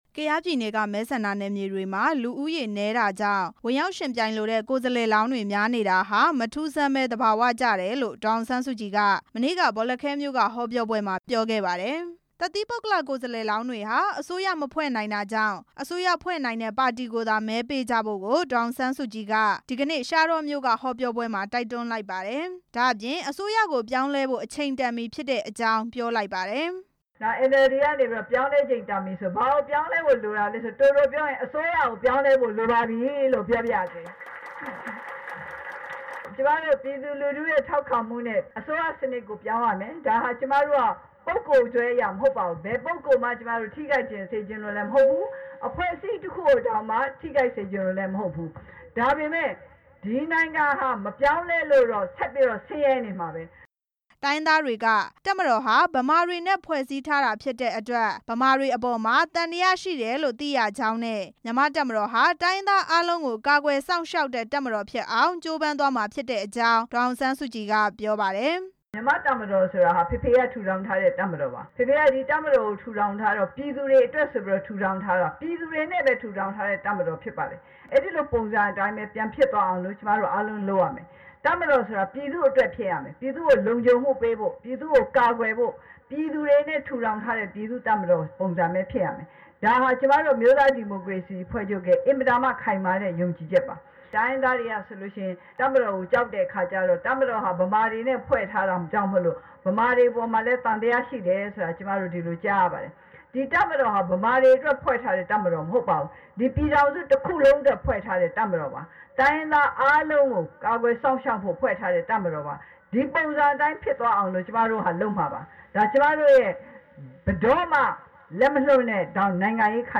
ဒီကနေ့ ကယားပြည်နယ် ရှားတောမြို့က ကေ့ထျိုးဘိုးကွင်းပြင်မှာ ရွေးကောက်ပွဲဆိုင်ရာ အသိပညာပေး ဟောပြော ပွဲမှာ ဒေါ်အောင်ဆန်းစုကြည်က တစ်သီးပုဂ္ဂလ ကိုယ်စားလှယ်တွေဆိုတာ အစိုးရမဖွဲ့နိုင်ကြောင်းနဲ့ ပါတီတွေကသာ အစိုးရဖွဲ့နိုင်တဲ့အတွက် ပါတီတွေကိုသာ မဲပေးကြဖို့ ပြောကြားလိုက်တာ ဖြစ်ပါတယ်။